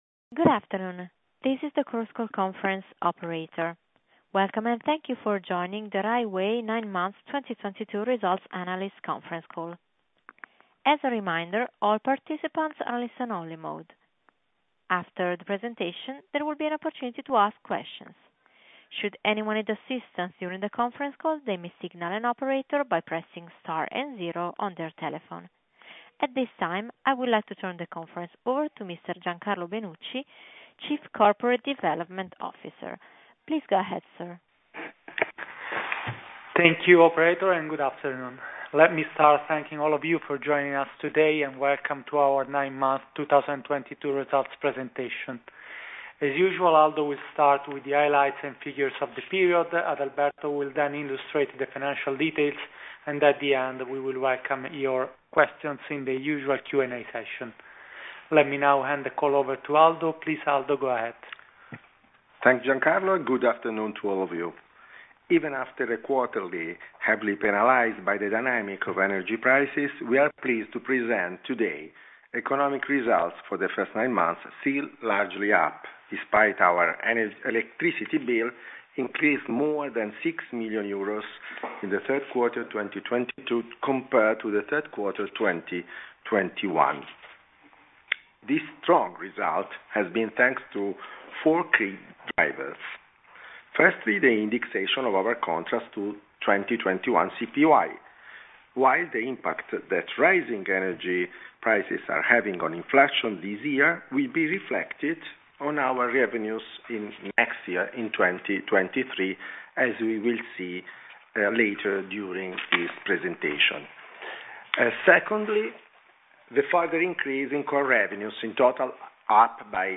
9M2022 results mp3 conference call.mp3